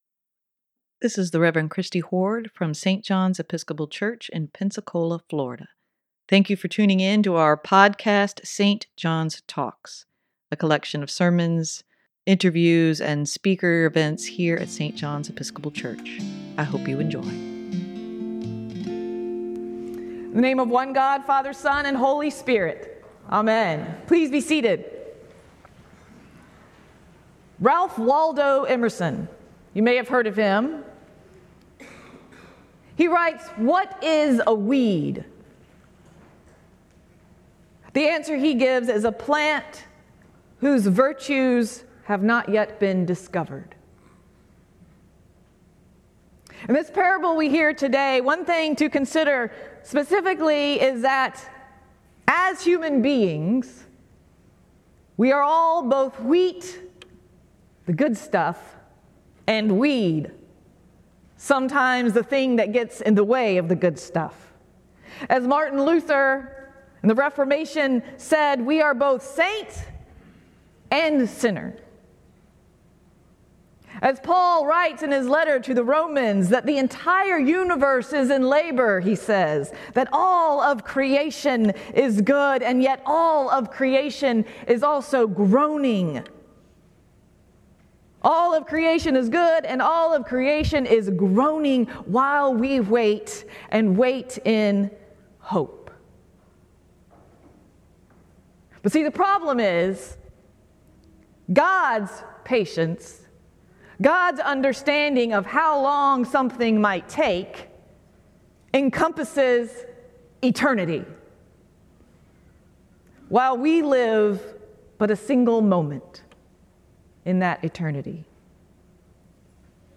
Sermon for July 23, 2023: Living among the weeds
sermon-7-23-23.mp3